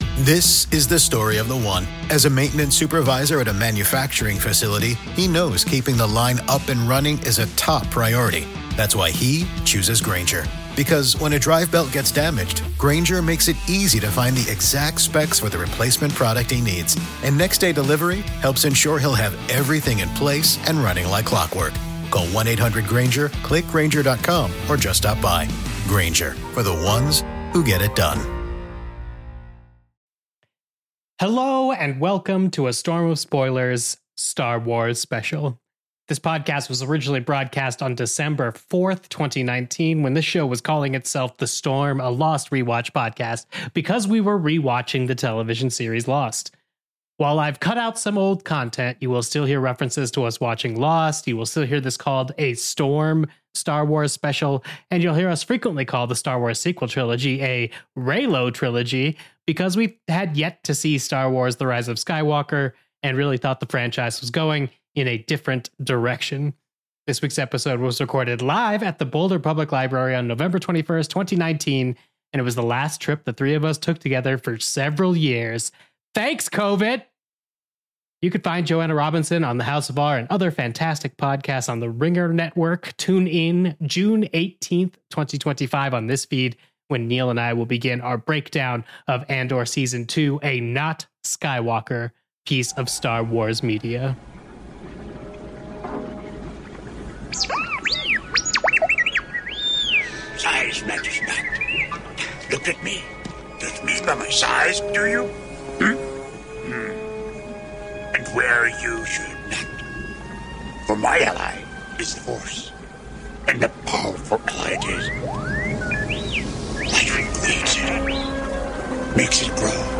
Today, it's our LIVE SHOW from the Canyon Theater at the Boulder Public Library in Colorado. This show was mostly recorded on November 21st, 2019 when your three hosts discussed A New Hope (Star Wars) , The Empire Strikes Back , and Return of the Jedi .